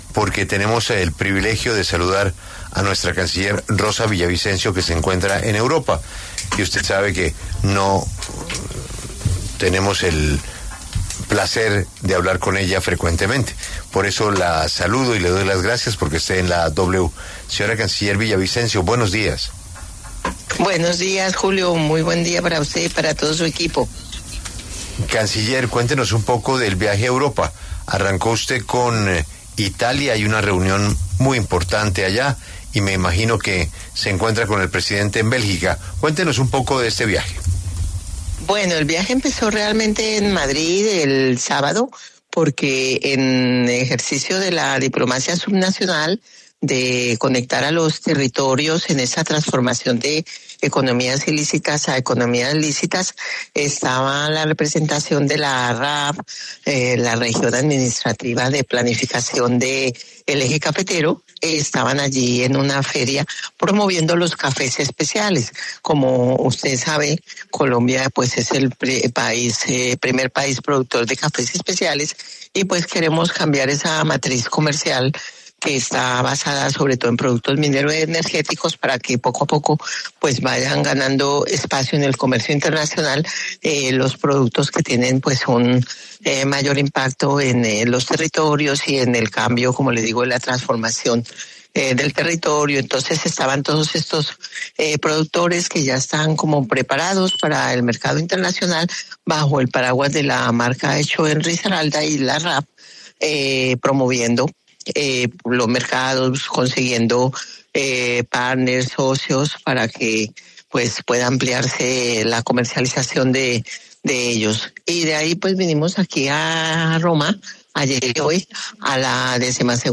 En diálogo con La W, la canciller Rosa Villavicencio explicó que ya fueron liberadas las dos colombianas que habían sido detenidas por Israel cuando iban rumbo a Palestina en la flotilla que llevaba ayuda humanitaria.
Escuche la entrevista completa con la canciller aquí: